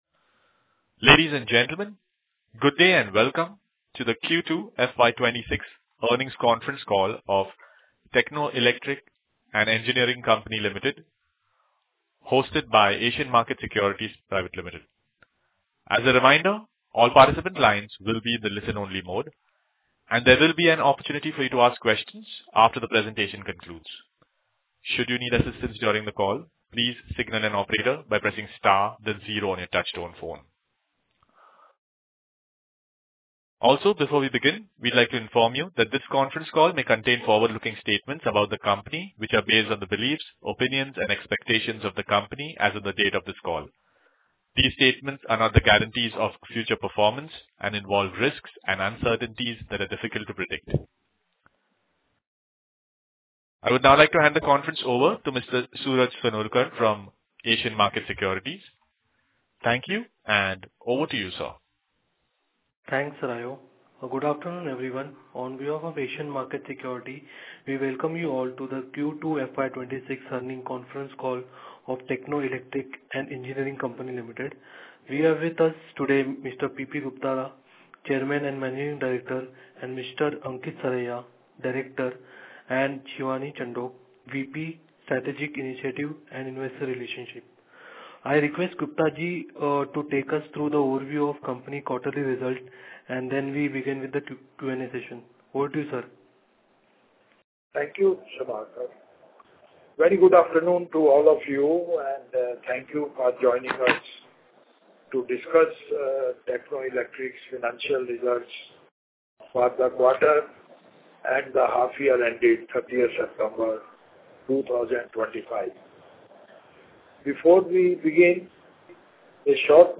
Conference Call FY26 Q3